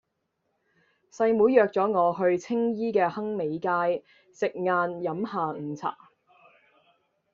Голоса - Гонконгский 282